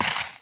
pedology_sand_footstep.3.ogg